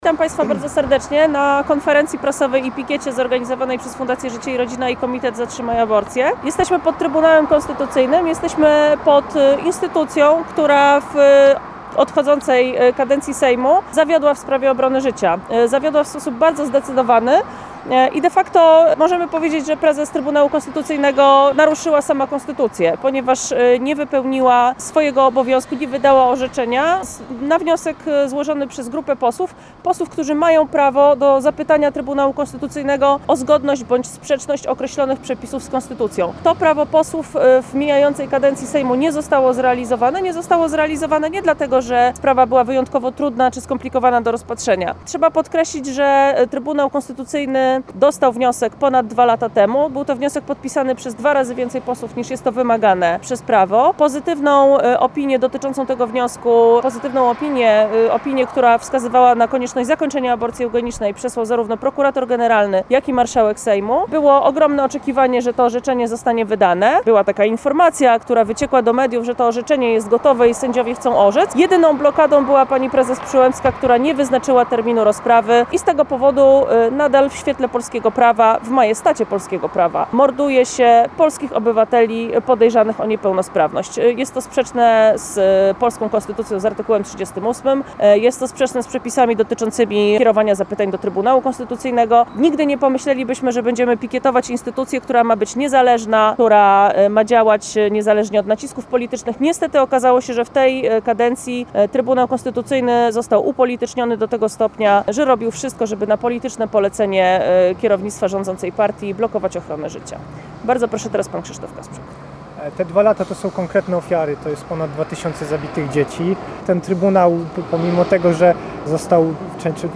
Nagranie z konferencji prasowej i pikiety Fundacji Życie i Rodzina: